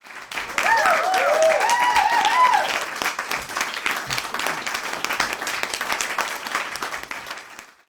crowdCheer.ogg